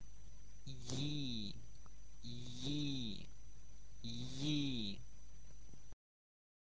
¯_¿ - there are two sound [ji] , like in English words yield, yielding, ye.